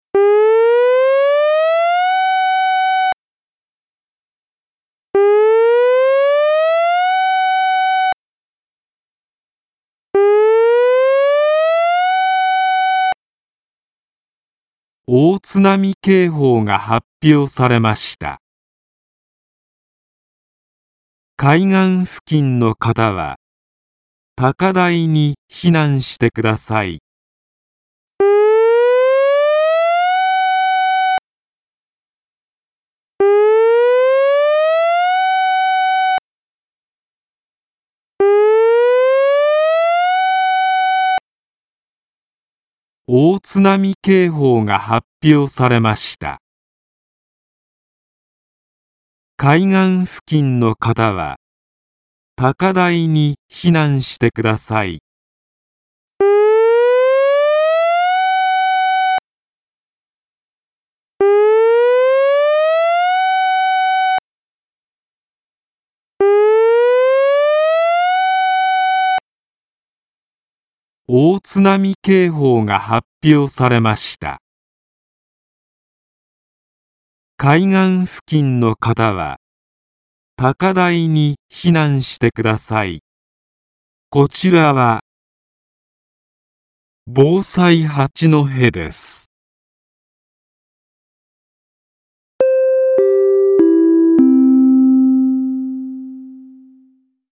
防災行政無線は、市民の皆様の安全安心のため、以下のような緊急時に、サイレンと音声で情報をお伝えします。
大津波警報 3秒吹鳴2秒休止×3回＋音声
「大津波警報」試聴する(ミュージック:1.5MB)